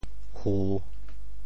仆（僕） 部首拼音 部首 亻 总笔划 4 部外笔划 2 普通话 pū pú 潮州发音 潮州 hu3 文 pog4 文 bog8 文 中文解释 僕〈動〉 向前跌倒 [fall forward] 僕,頓也。